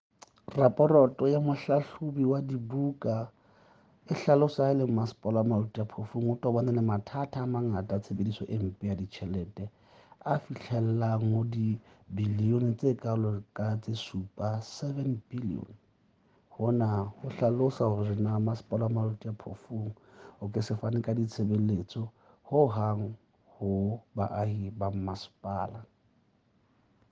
Sesotho by Cllr Moshe Lefuma.